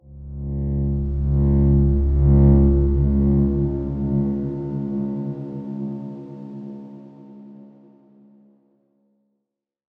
X_Darkswarm-C#1-pp.wav